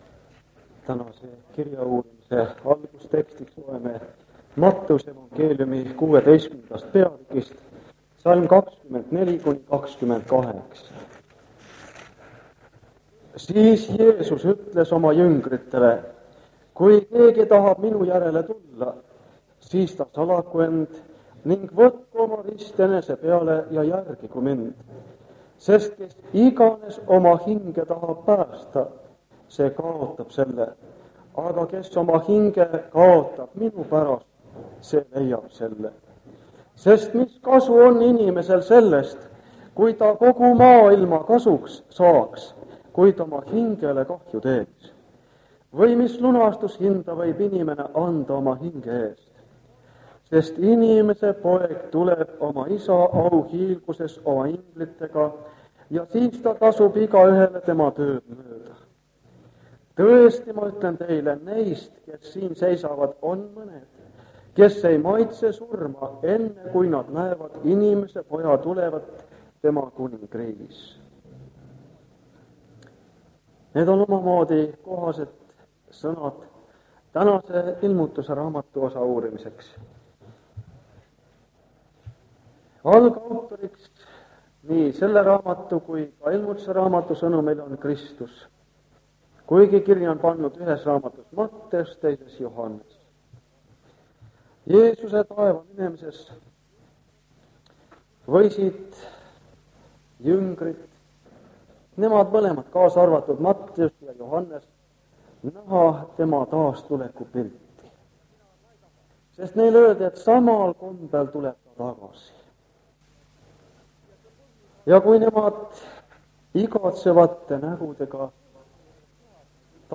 Jutlused
Ilmutuse raamatu seeriakoosolekud Kingissepa linna adventkoguduses
Kahjuks on üsna kehv lindistus osal 30. Vana lintmaki lint Osa nr. 31 on puudu Osa 32 - Ülevaade Ilmutuse 1-15 peatükkidest